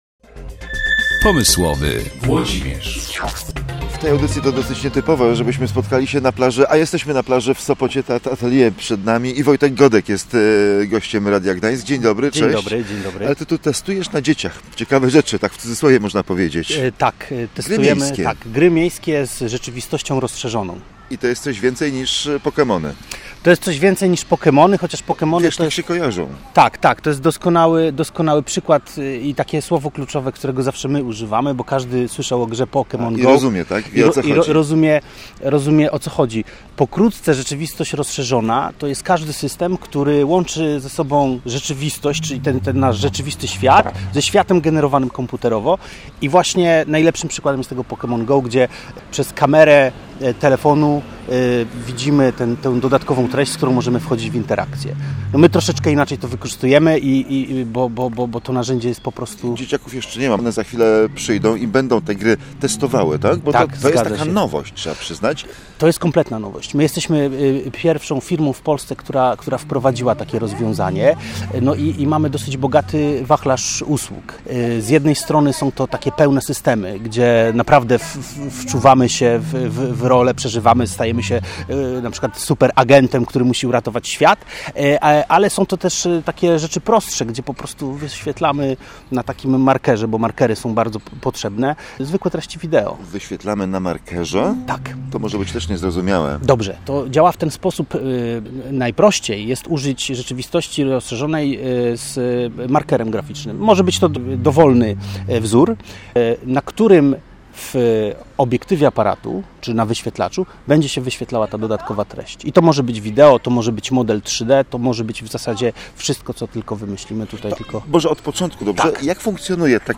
Posłuchaj rozmowy: /audio/dok2/pomysl-gry.mp3 Pierwszy projekt powstał dla Muzeum Gdańska.